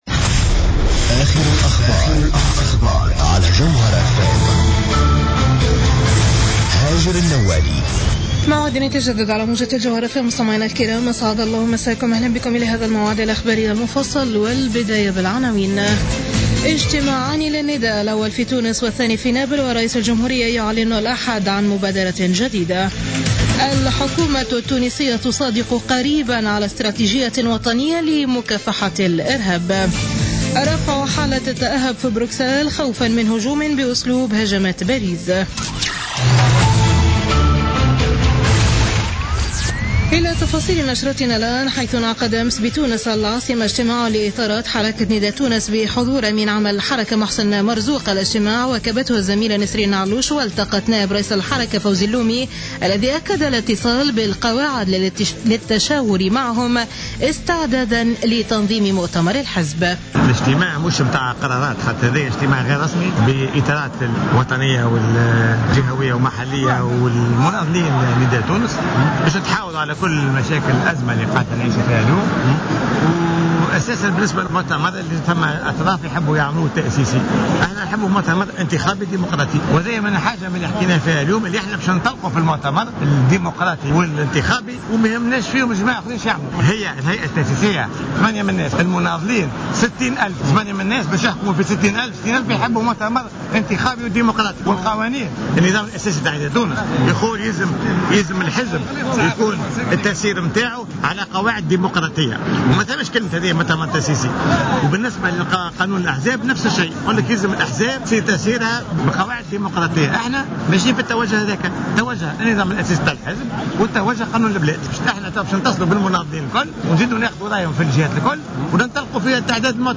نشرة أخبارمنتصف الليل ليوم اللأحد 22 نوفمبر 2015